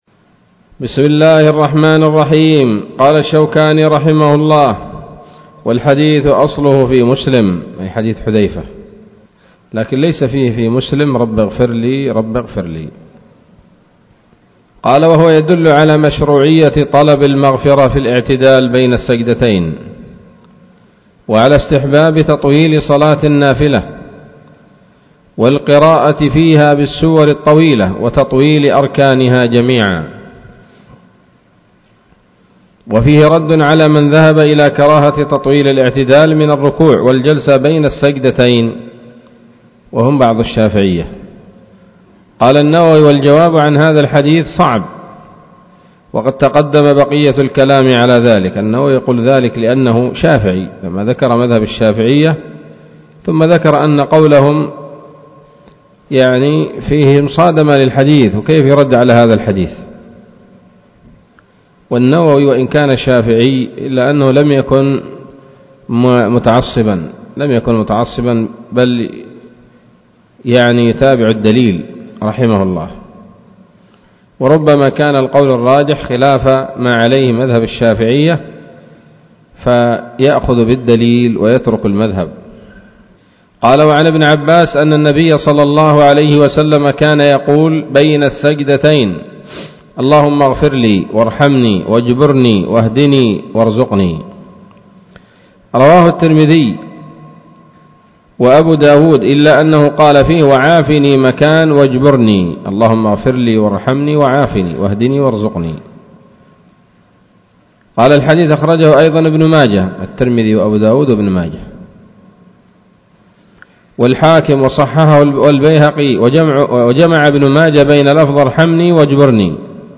الدرس السادس والستون من أبواب صفة الصلاة من نيل الأوطار